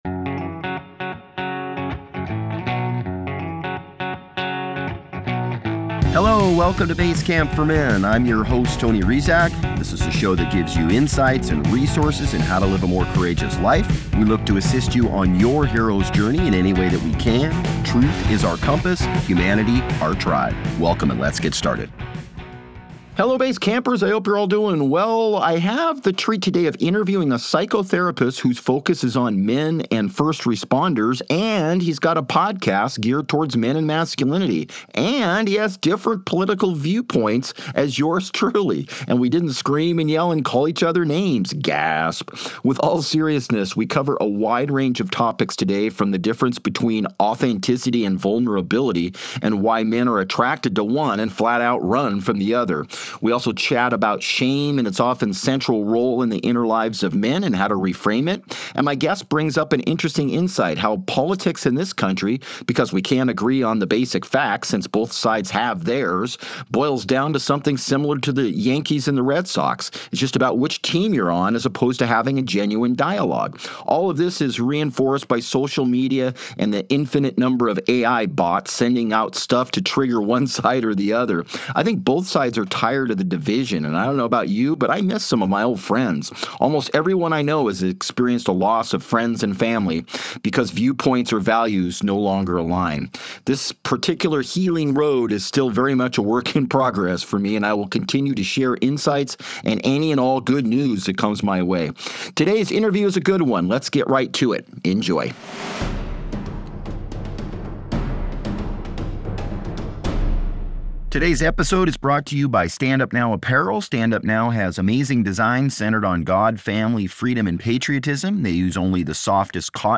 I have the treat today of interviewing a psychotherapist who’s focus is on men and first-responders.